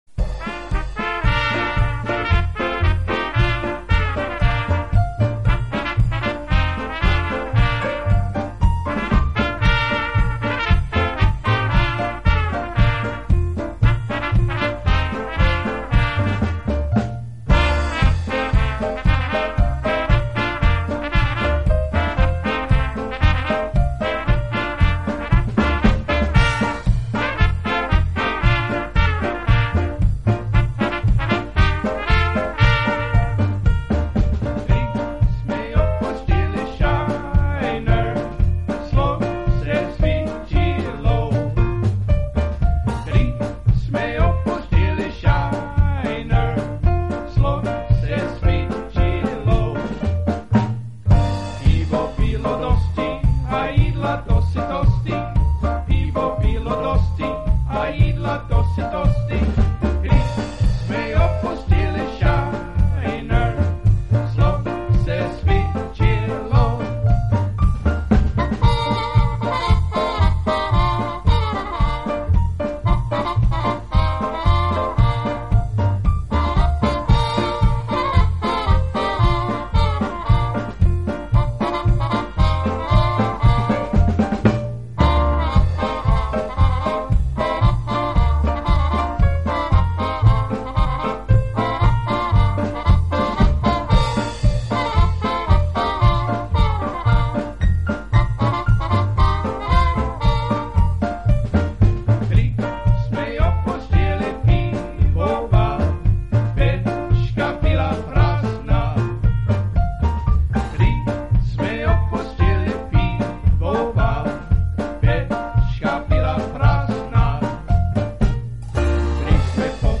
Commentary 7.